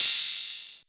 • Open High-Hat F Key 29.wav
Royality free open hi hat sound tuned to the F note. Loudest frequency: 6539Hz
open-high-hat-f-key-29-CzX.wav